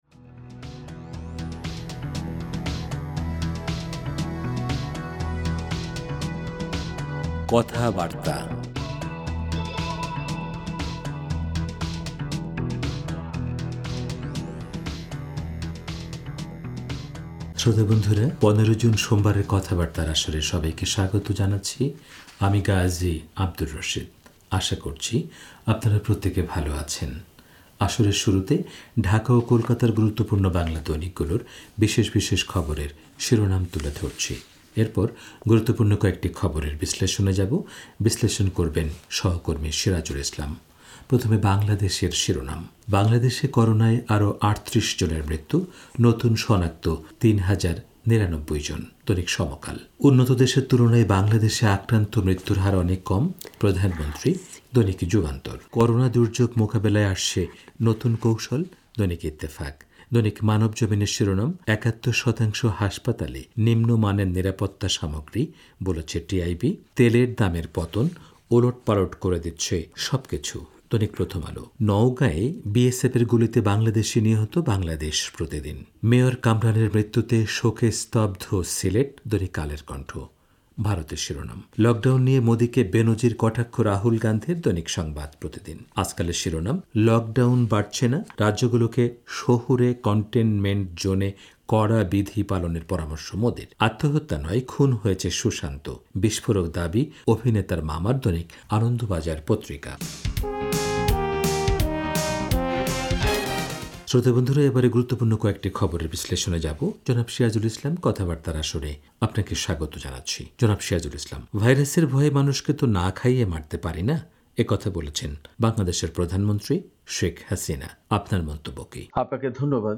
রেডিও